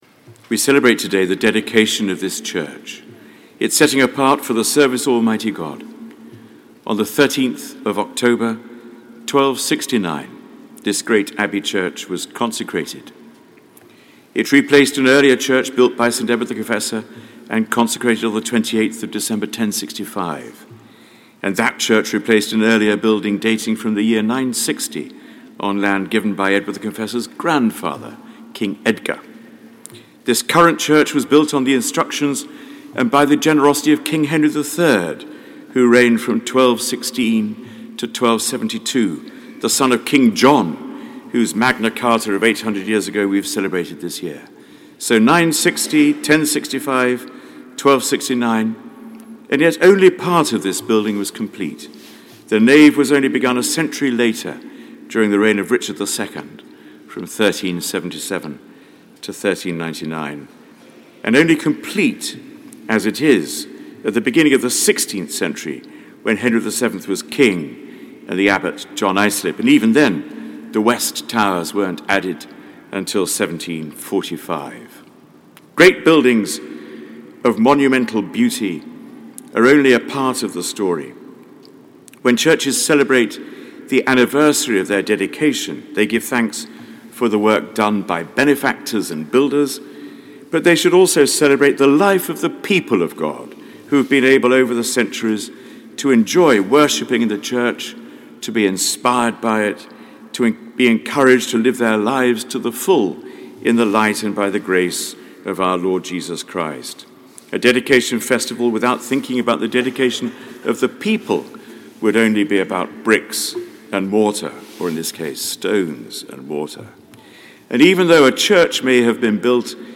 Sermon given at the Sung Eucharist for the Dedication of Westminster Abbey - 2015
Sermon given by The Very Reverend Dr John Hall, Dean of Westminster at the Sung Eucharist for the Dedication of Westminster Abbey on Sunday 18th October 2015